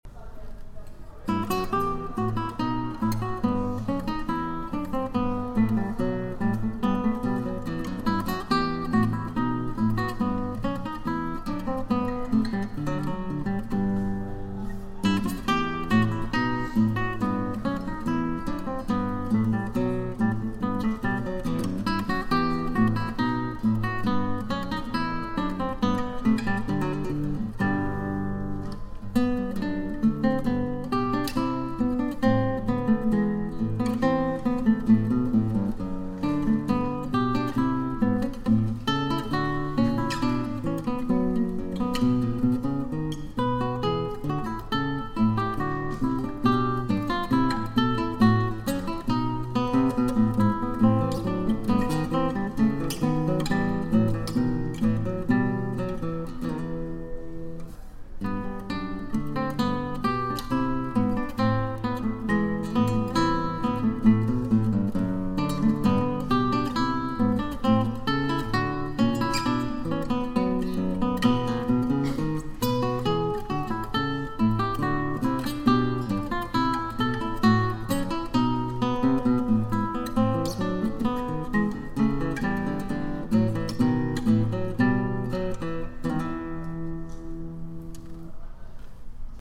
Binary form AA BB or (A)(B) - parentheses are to indicate a section played twice
Bach Bourree AABB